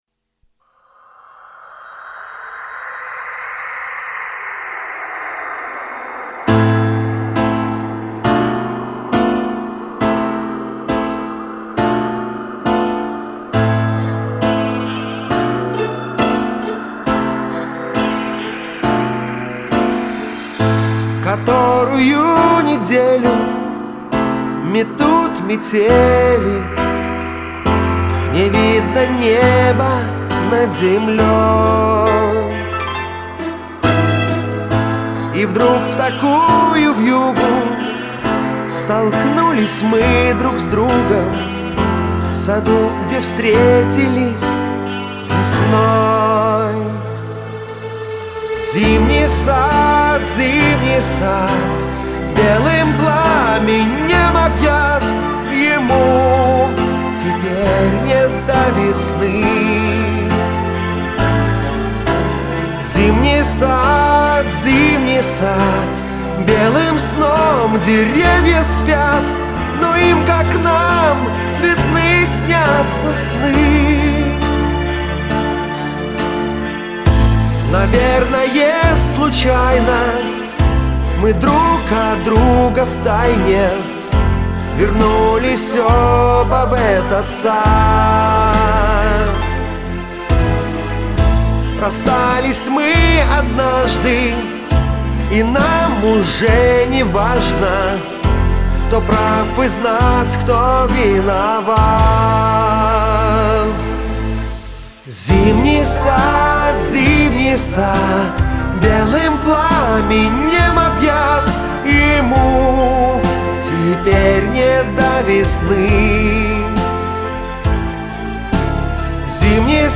поет всегда чисто и ровно